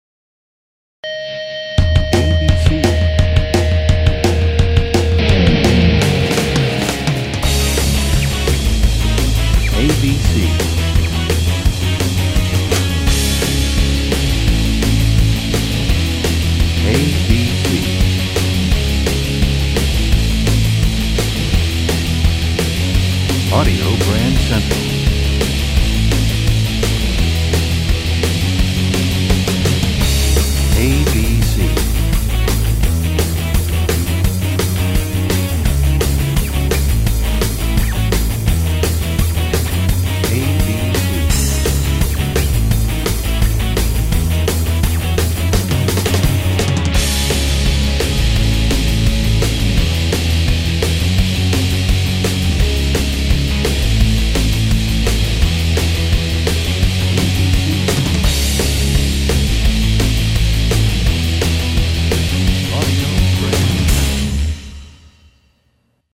Genre: Theme Music.